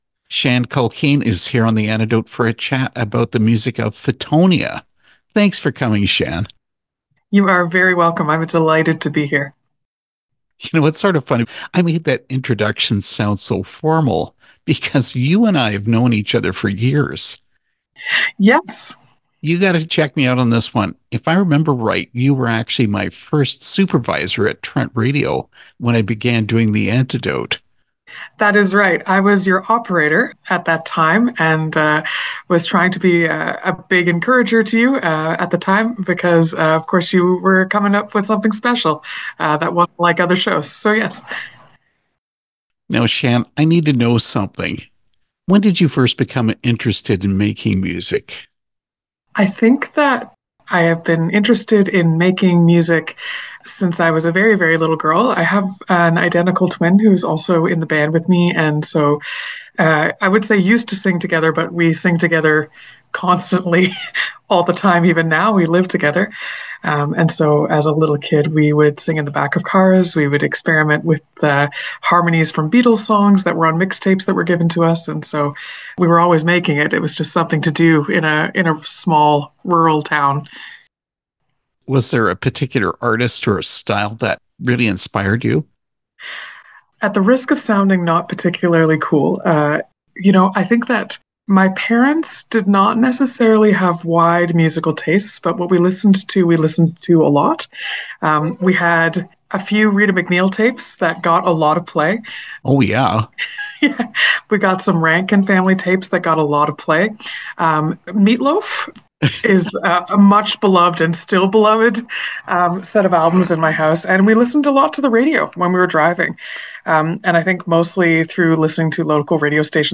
Interview with Fittonia
fittonia-interview.mp3